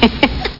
Amiga 8-bit Sampled Voice
laugh.mp3